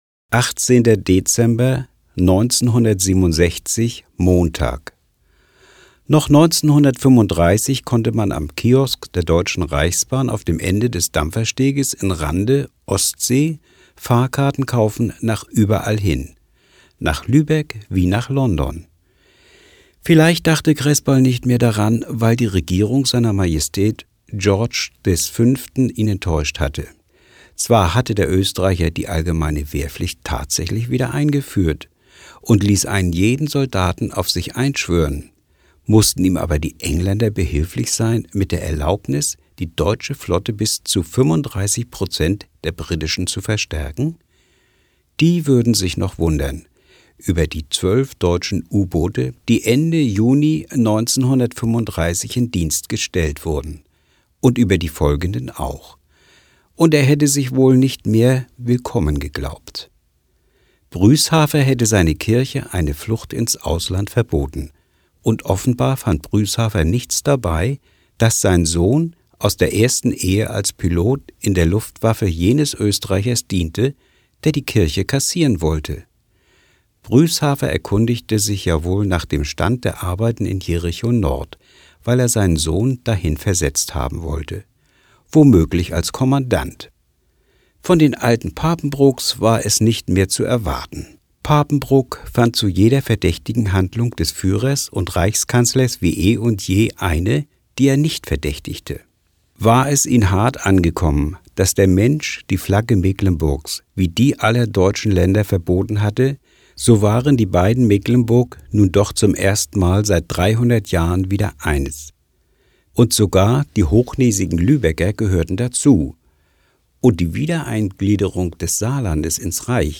Eine Stadt liest Uwe Johnsons Jahrestage - 18.